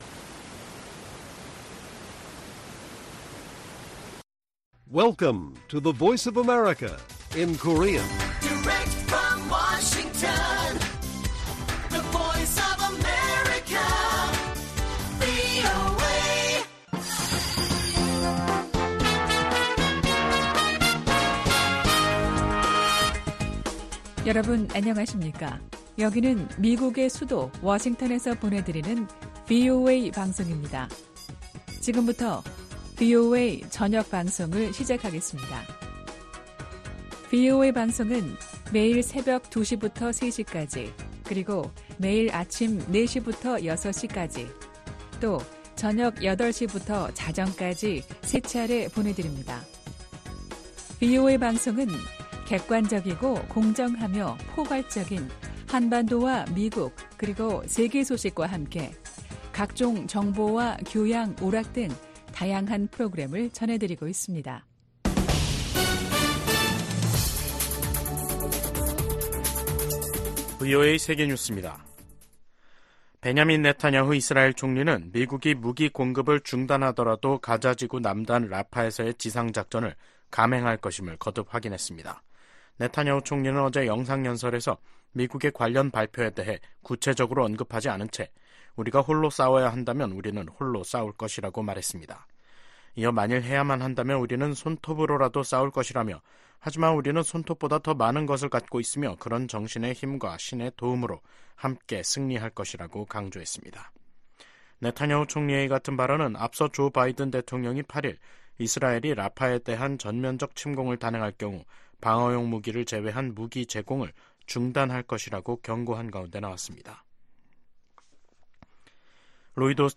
VOA 한국어 간판 뉴스 프로그램 '뉴스 투데이', 2024년 5월 10일 1부 방송입니다. 북한 가상화폐 계좌에 대한 몰수 소송을 담당한 워싱턴 DC 연방법원이 해당 계좌 270여개에 대한 몰수를 명령했습니다. 중국이 최근 탈북민 60여 명을 강제 북송한 것으로 알려진 가운데 미 국무부 북한인권특사가 이에 대한 심각한 우려를 표시했습니다.